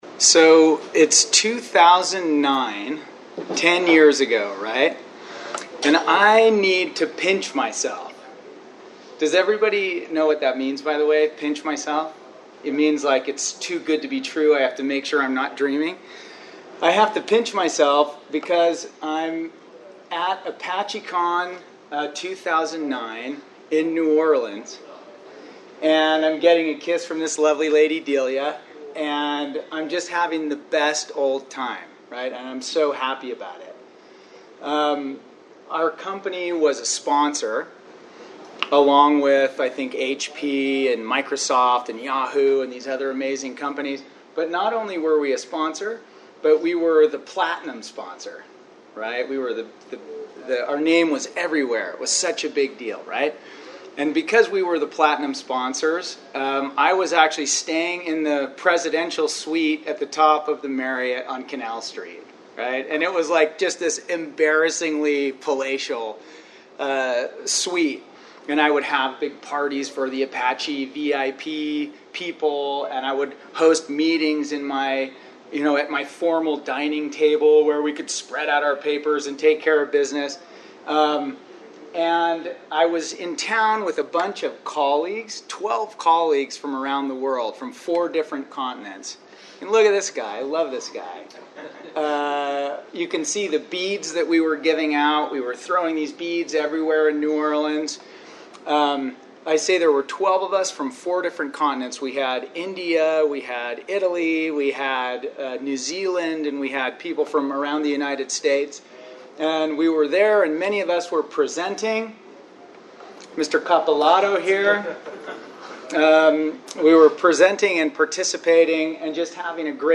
The presenter will share examples of one service provider’s approach to making community contributions, including those of individuals as well as company-wide initiatives that have worked well over the years.